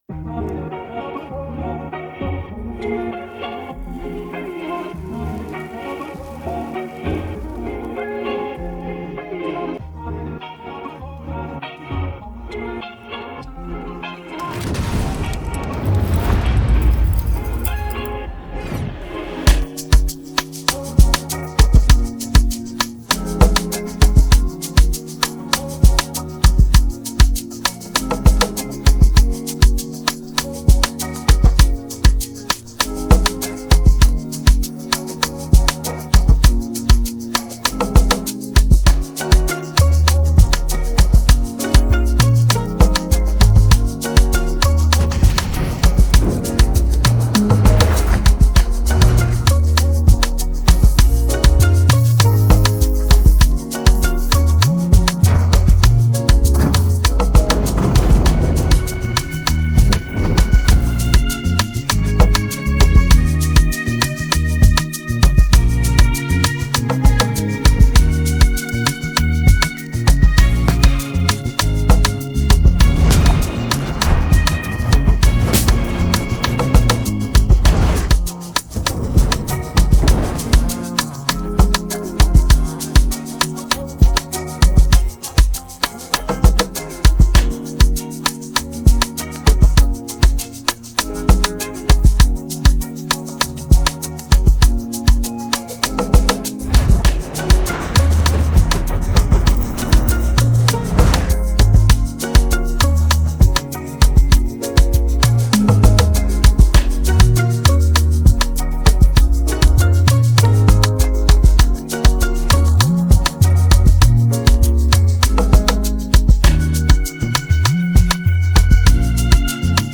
AfrobeatsAmapaino